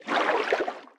Sfx_creature_brinewing_swim_slow_01.ogg